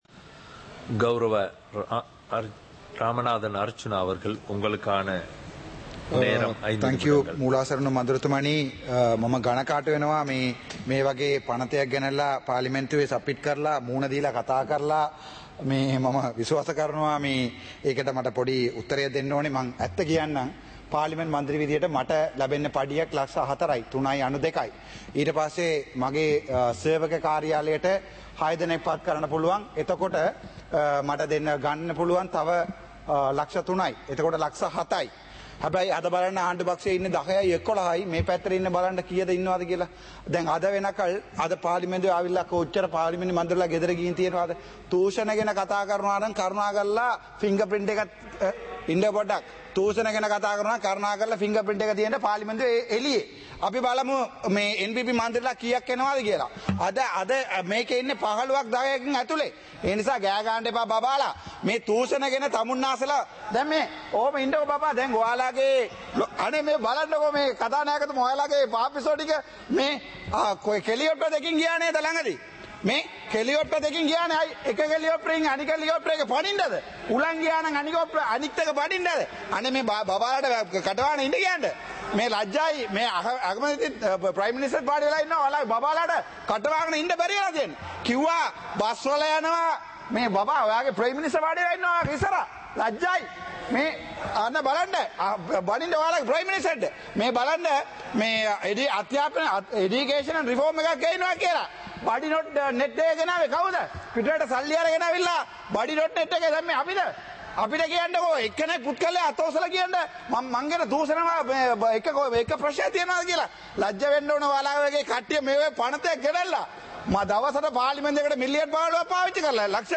சபை நடவடிக்கைமுறை (2026-02-18)
நேரலை - பதிவுருத்தப்பட்ட